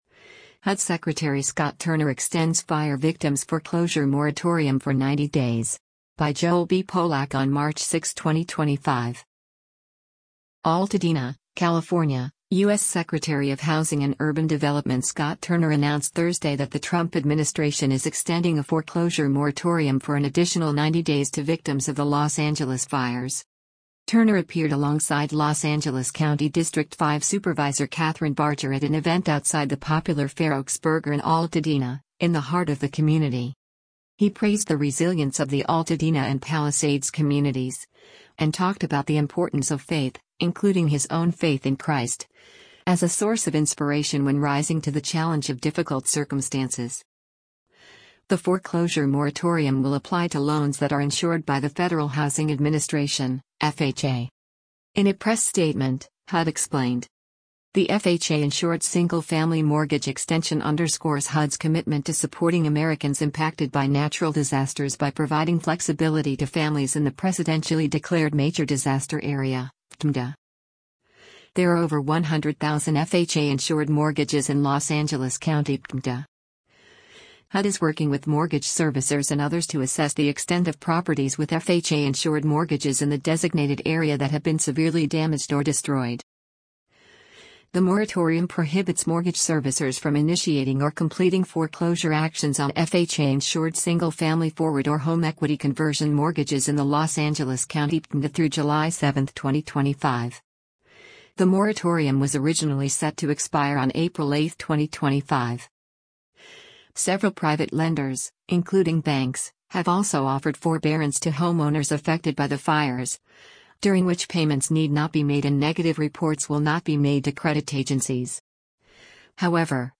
Turner appeared alongside Los Angeles County District 5 Supervisor Kathryn Barger at an event outside the popular Fairoaks Burger in Altadena, in the heart of the community.